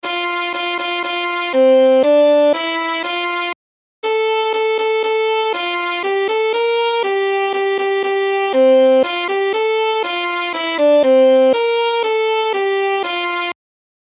Aranžmá Noty na housle
Hudební žánr Lidovky